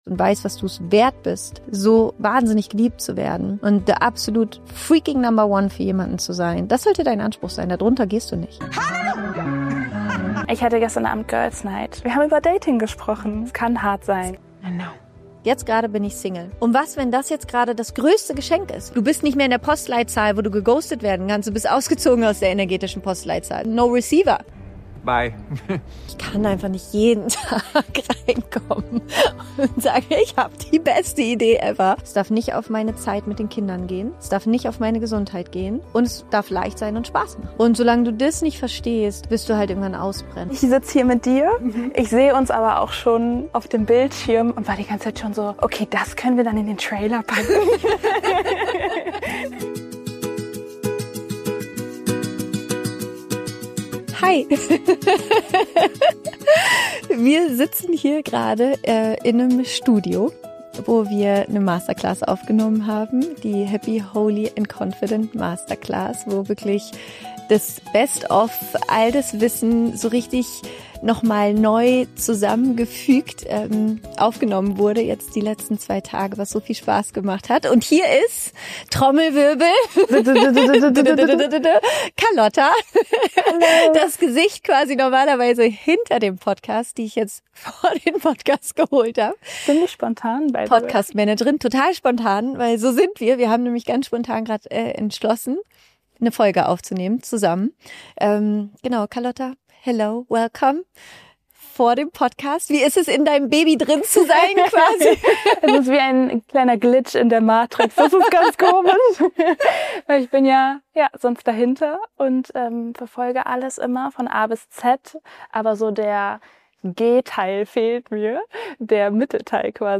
Die Person HINTER dem Podcast, kommt heute vor das Mikro und stellt mir 11 schnelle Fragen.